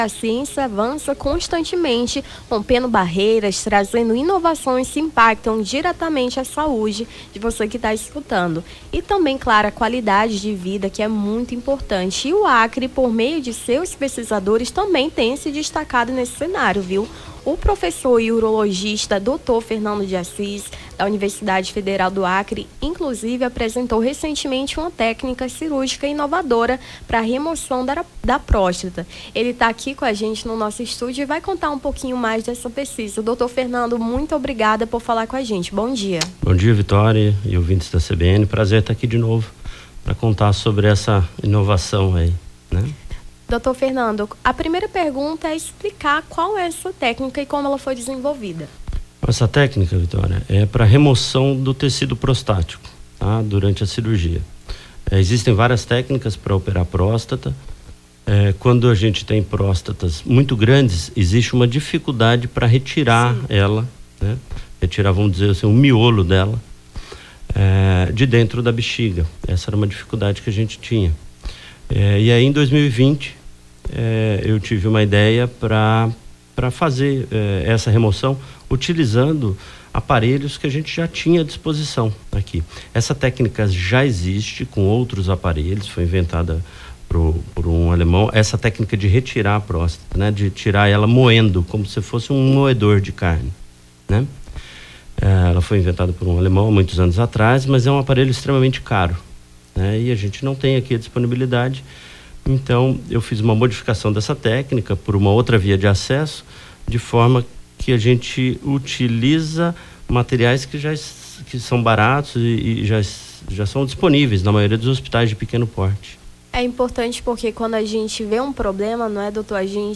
Nome do Artista - CENSURA - ENTREVISTA CIRURGIA PRÓSTATA UFAC (17-12-24).mp3